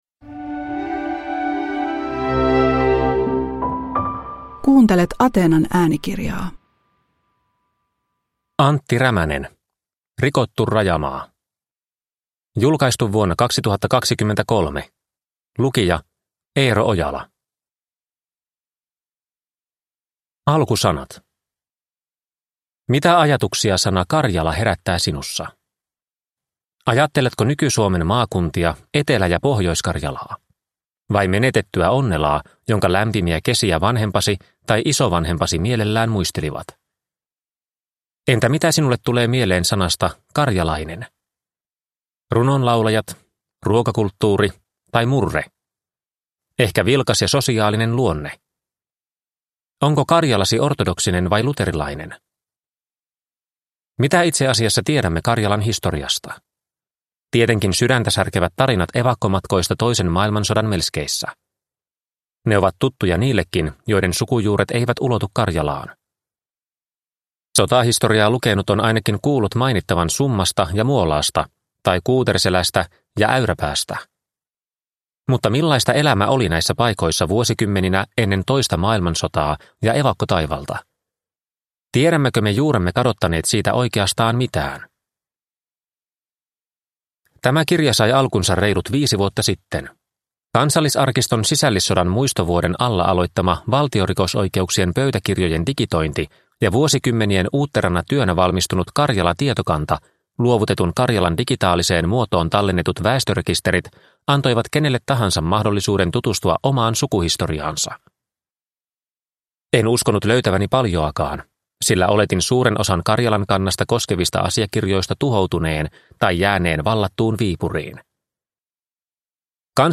Rikottu rajamaa – Ljudbok – Laddas ner